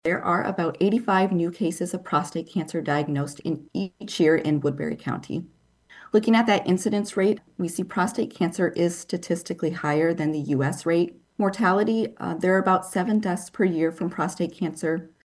CANCER RATES IN WOODBURY COUNTY WERE THE MAIN TOPIC OF DISCUSSION AT A HEALTH FORUM AT WESTERN IOWA TECH THURSDAY MORNING.